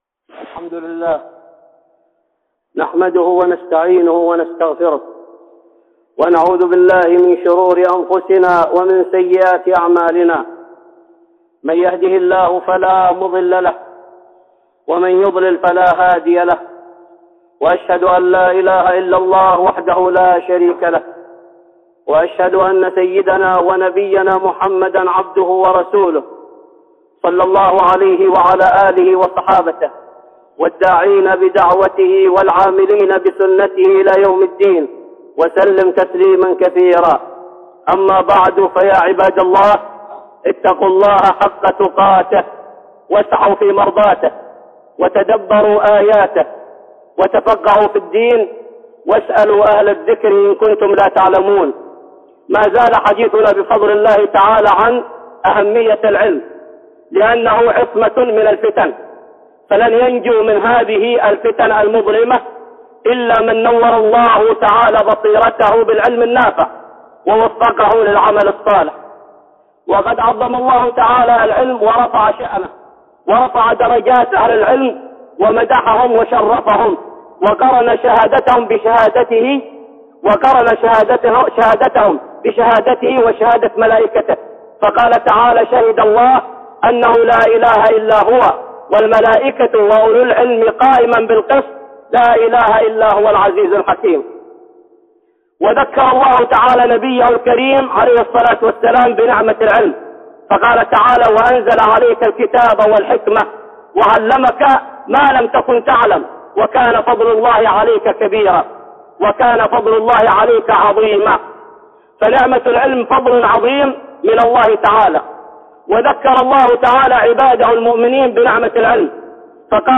(خطبة جمعة) أهمية العلم الشرعي